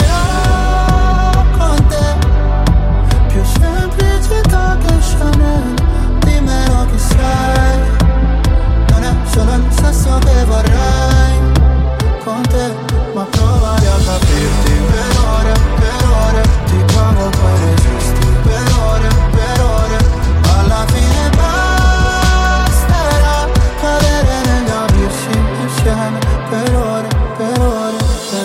Genere: pop,disco,trap,rap,dance,hit